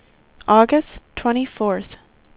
speech / tts / prompts / voices